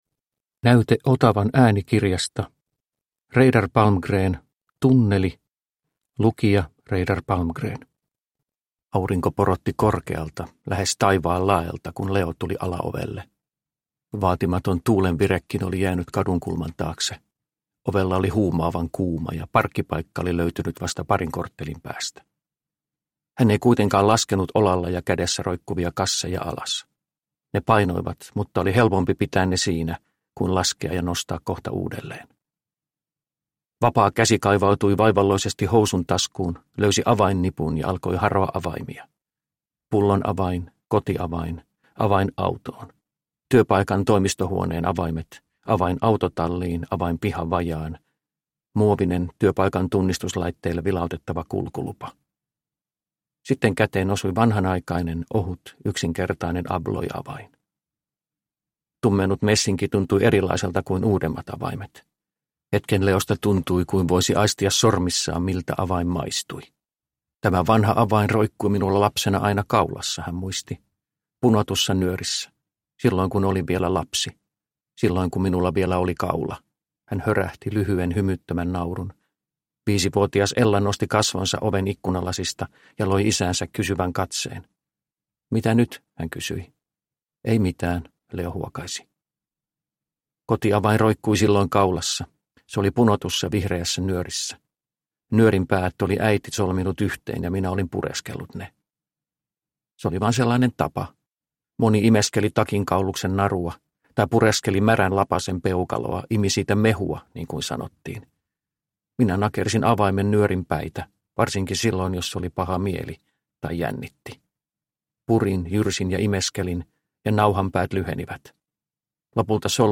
Tunneli – Ljudbok – Laddas ner